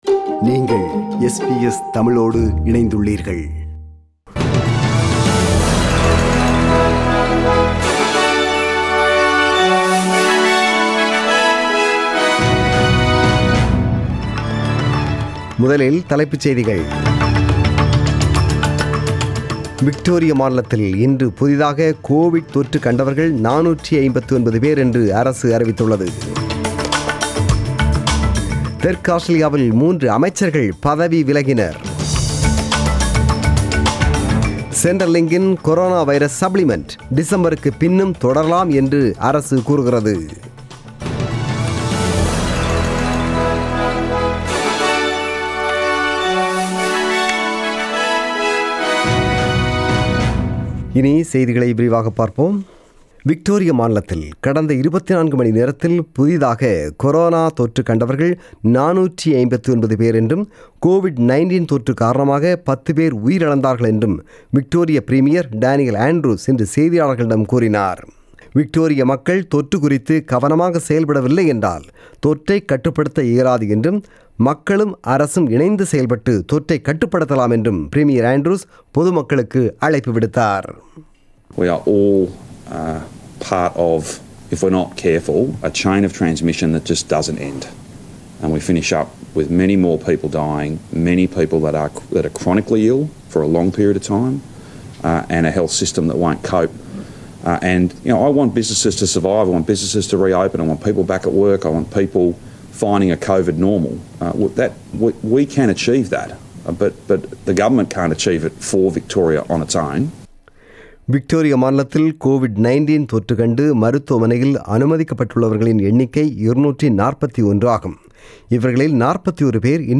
The news bulletin was broadcasted on 26 July 2020 (Sunday) at 8pm.